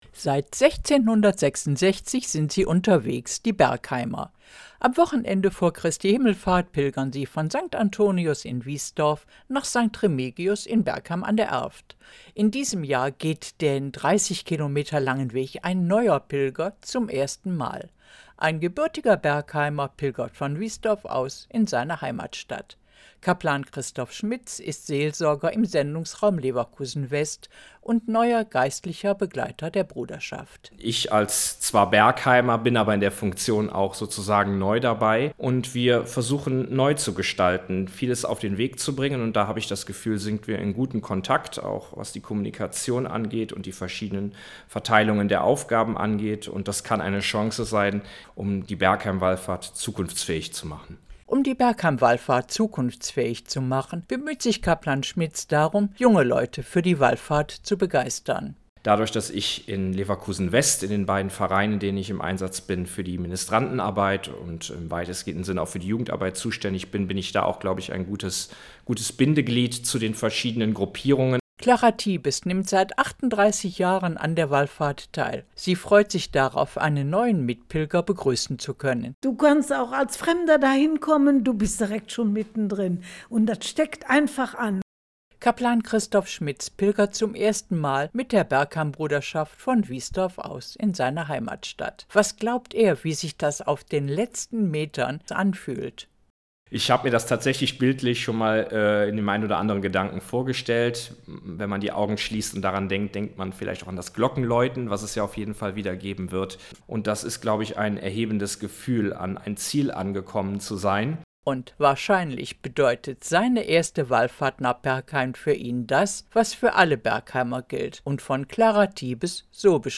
Es enthält eine Aufzeichnung von Radio Leverkusen